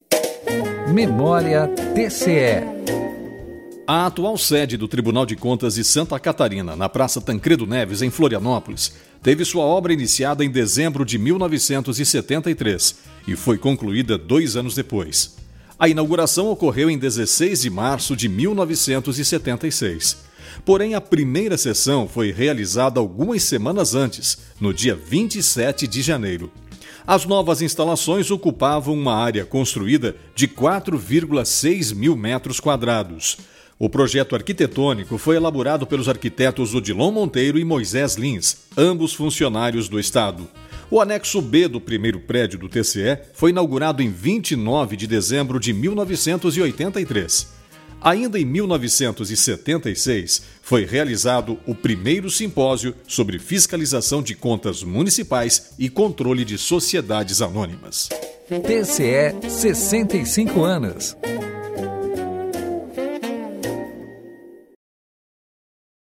VINHETA – MEMÓRIA TCE
VINHETA – TCE - 65 ANOS